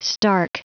Prononciation du mot stark en anglais (fichier audio)
Prononciation du mot : stark